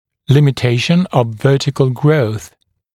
[ˌlɪmɪ’teɪʃn əv ‘vɜːtɪkl grəuθ][ˌлими’тэйшн ов ‘вё:тикл гроус]ограничение вертикального роста